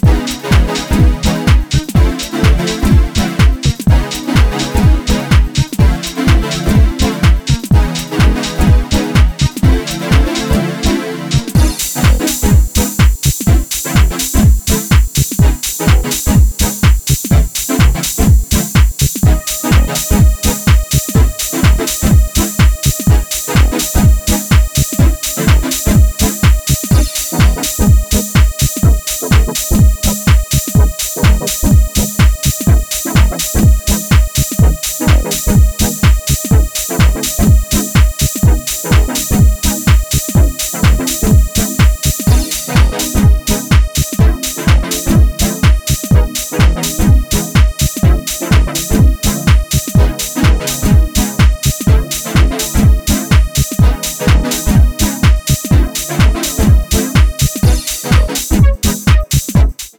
ハウスに流れるラテンの遺伝子をモダンなタッチで強調。